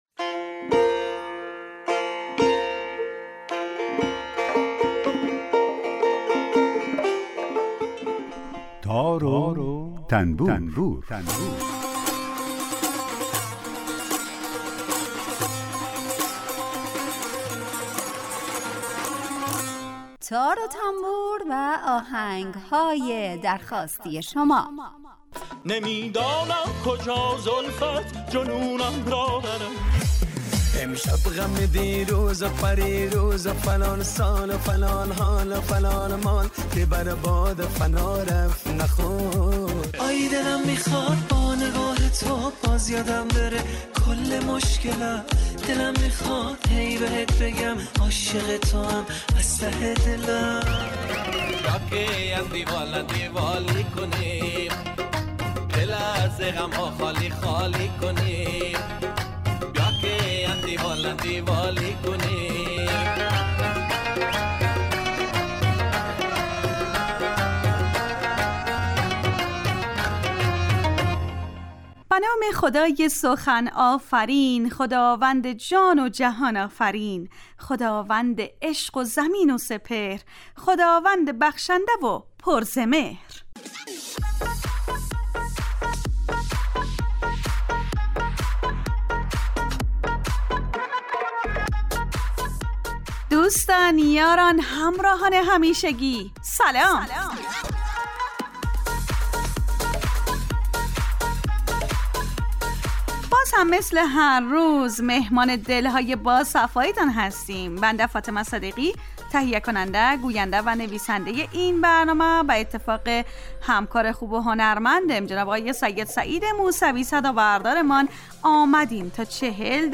برنامه تار و تنبور هر روز از رادیو دری به مدت 40 دقیقه برنامه ای با آهنگ های درخواستی شنونده ها کار از گروه اجتماعی رادیو دری.